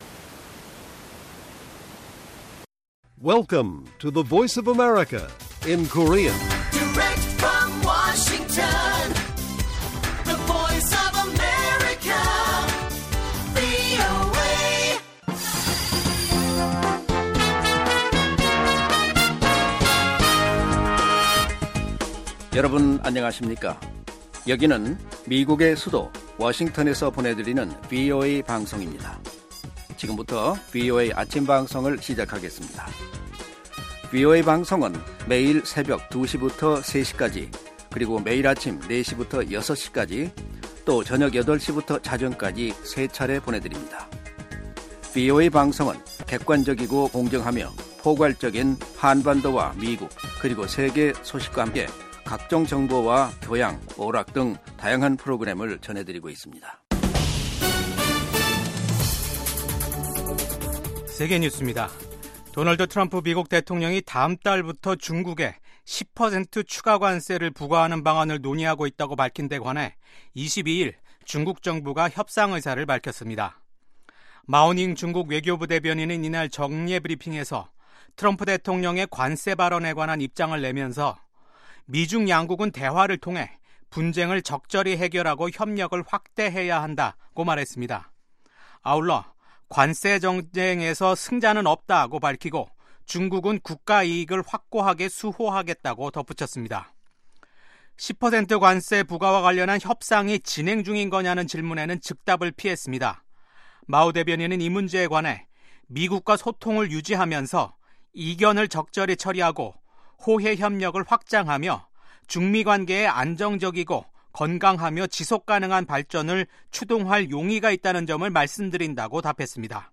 세계 뉴스와 함께 미국의 모든 것을 소개하는 '생방송 여기는 워싱턴입니다', 2025년 1월 23일 아침 방송입니다. 2월 1일부터 중국산 수입품에 10% 관세를 부과할 계획이라고 도널드 트럼프 미국 대통령이 밝혔습니다. 마르코 루비오 미국 국무부 장관이 21일 취임 선서를 하고 국제 외교무대에 데뷔했습니다. 유럽연합(EU)이 미국 정부와 협상할 준비가 됐다고 우르줄라 폰데어라이엔 EU 집행위원장이 밝혔습니다.